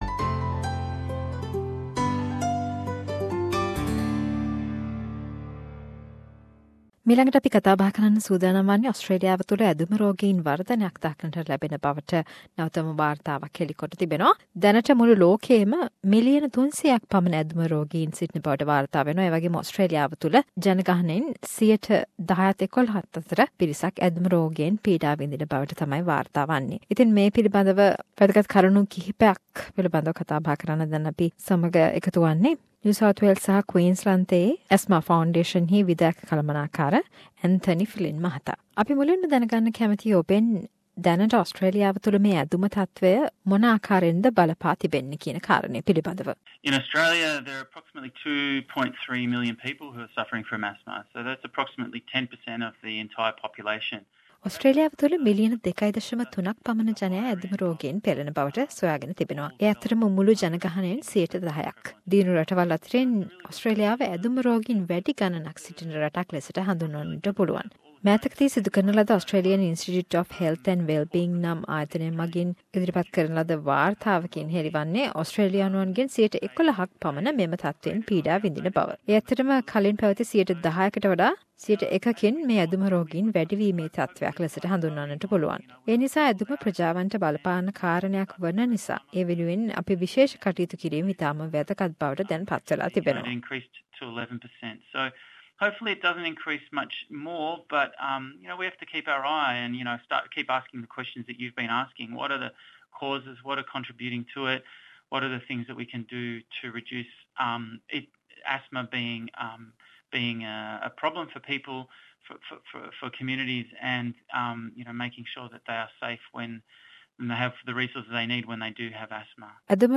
A chat with General Manager